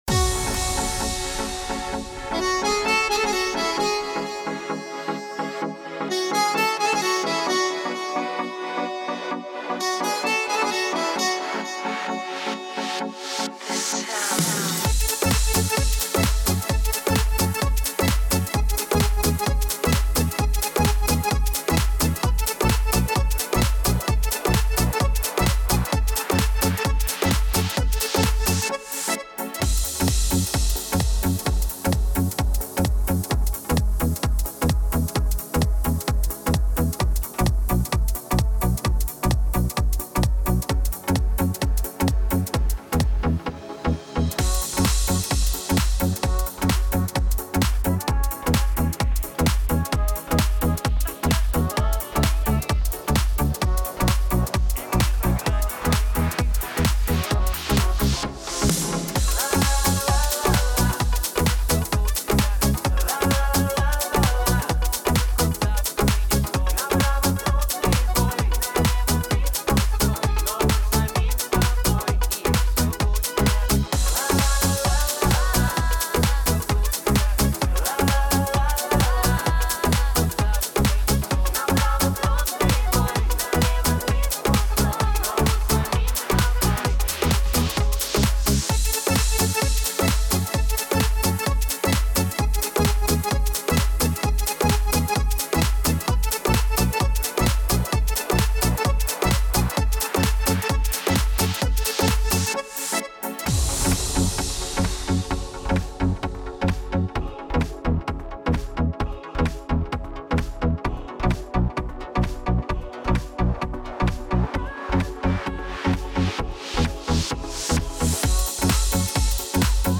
минусовка версия 54336